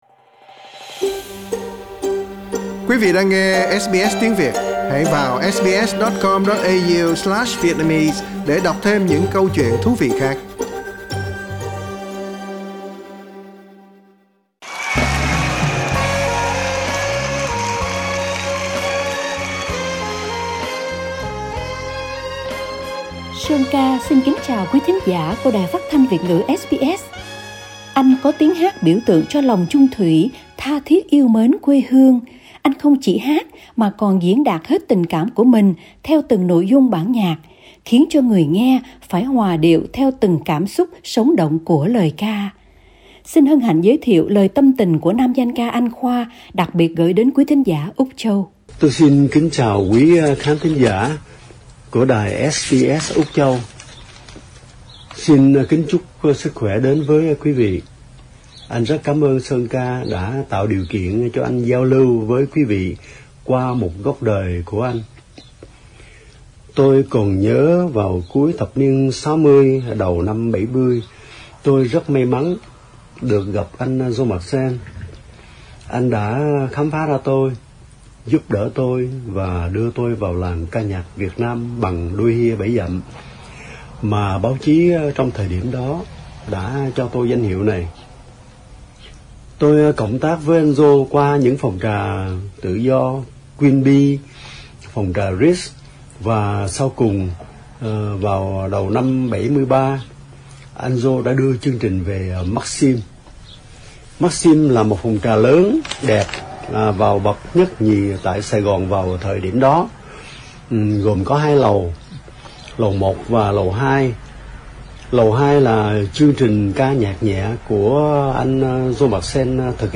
Xin hân hạnh giới thiệu lời tâm tình của nam danh ca Anh Khoa đặc biệt gởi đến quí thính giả Úc Châu.